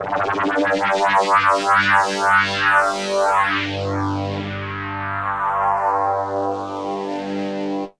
Session 08 - LFO Note.wav